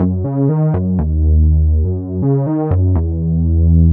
Slip Slide Bass_122_Gb.wav